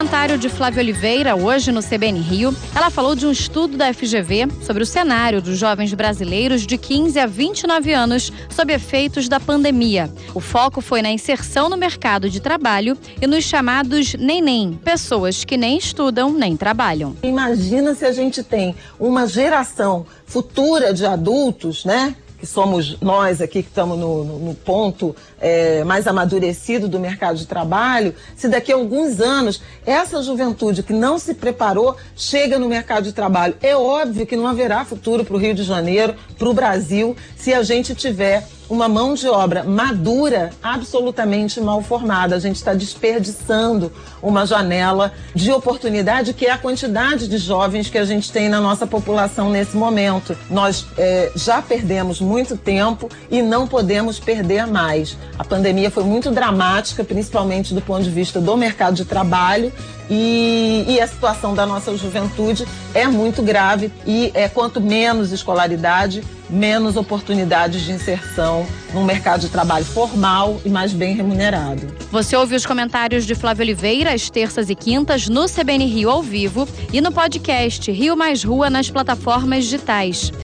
• Rádio